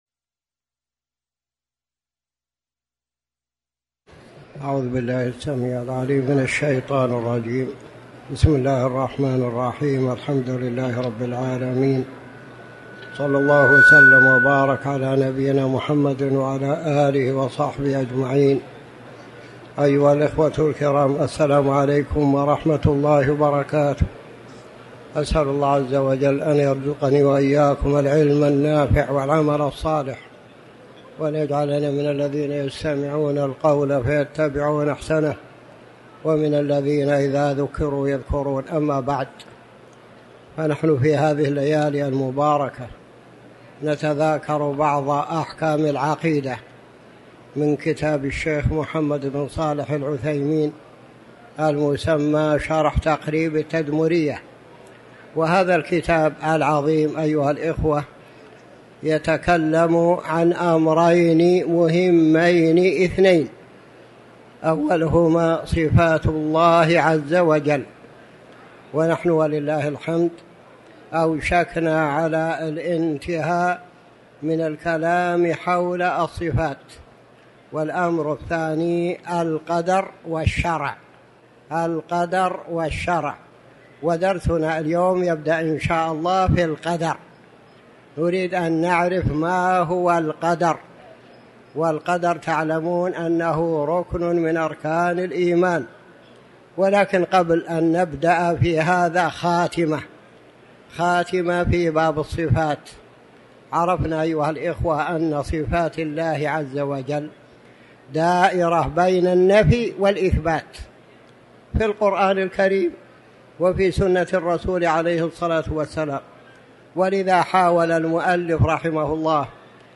تاريخ النشر ١٥ ربيع الثاني ١٤٤٠ هـ المكان: المسجد الحرام الشيخ